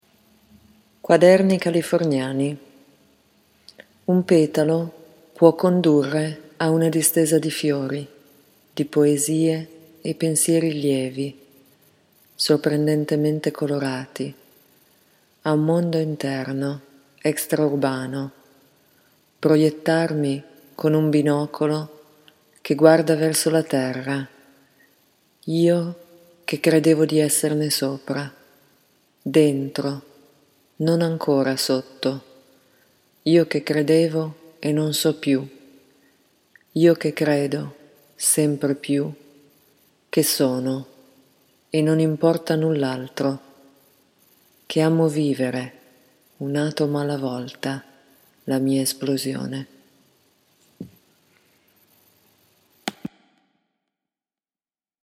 A reading of the poem by the poet can be heard by clicking on the player below: